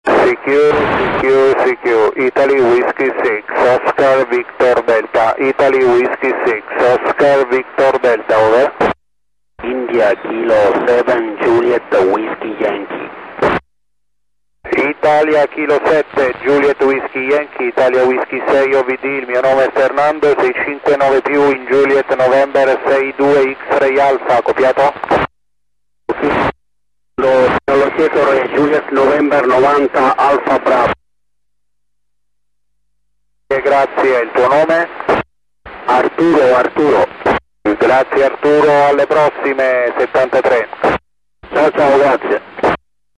modo operativo VOCE - uplink 437.800 Mhz FM , downlink 145.800 Mhz FM + - Doppler